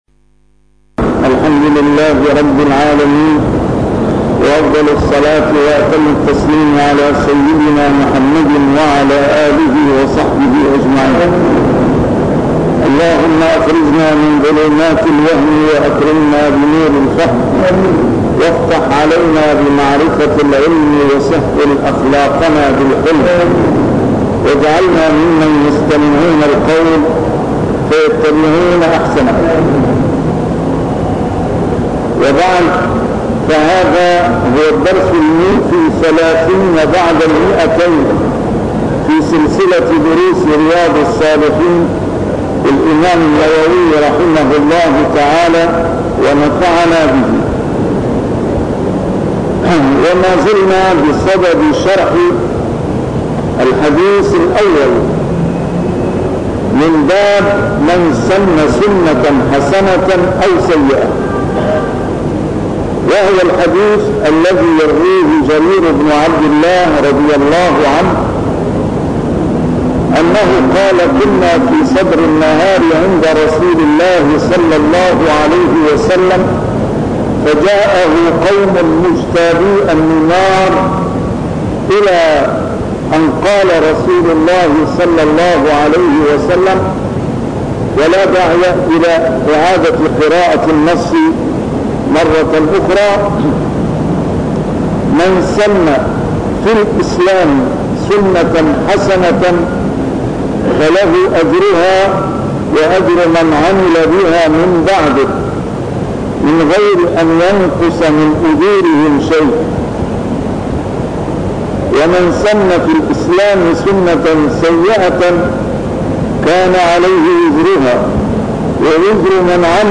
A MARTYR SCHOLAR: IMAM MUHAMMAD SAEED RAMADAN AL-BOUTI - الدروس العلمية - شرح كتاب رياض الصالحين - 230- شرح رياض الصالحين: فيمن سنَّ سنّةً